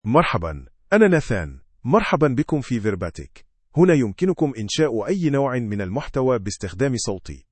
NathanMale Arabic AI voice
Nathan is a male AI voice for Arabic (Standard).
Voice sample
Male
Nathan delivers clear pronunciation with authentic Standard Arabic intonation, making your content sound professionally produced.